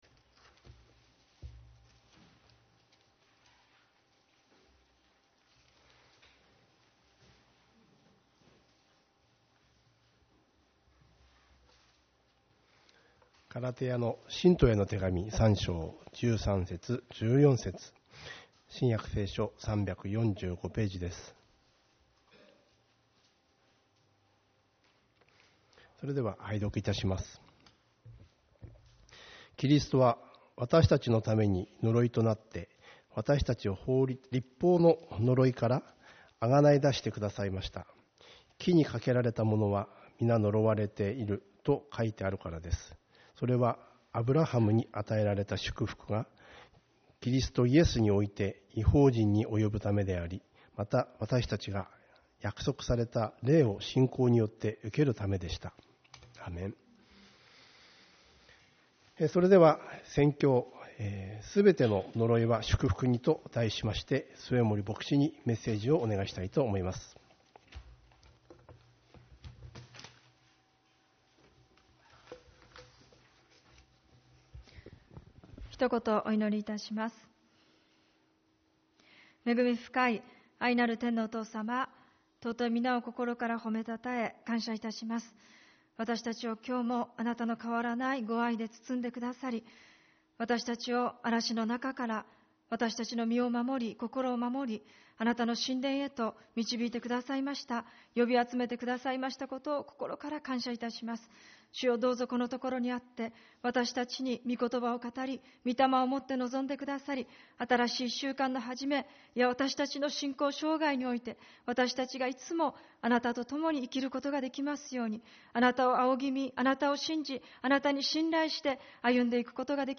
主日礼拝 「すべての呪いは祝福に｣ ガラテヤの信徒への手紙3:13-14